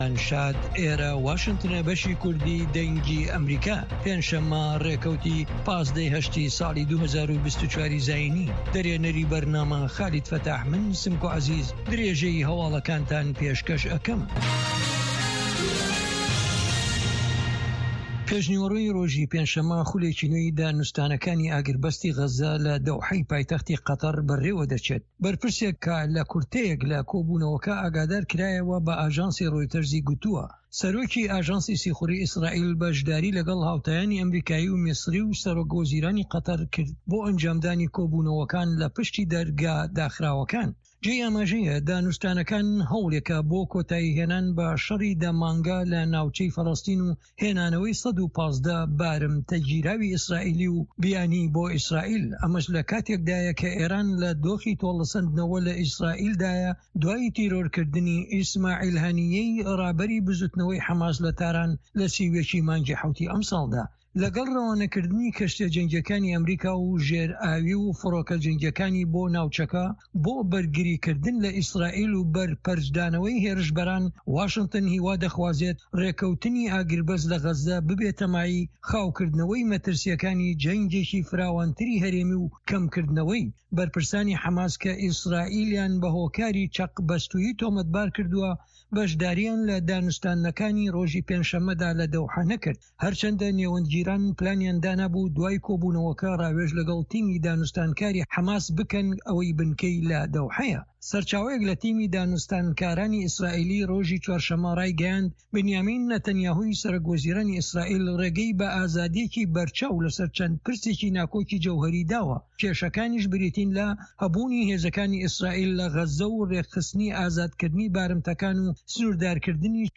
Nûçeyên 1’ê paşnîvro
Nûçeyên Cîhanê ji Dengê Amerîka